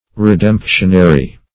redemptionary - definition of redemptionary - synonyms, pronunciation, spelling from Free Dictionary
Search Result for " redemptionary" : The Collaborative International Dictionary of English v.0.48: Redemptionary \Re*demp"tion*a*ry\ (-?*r?), n. One who is, or may be, redeemed.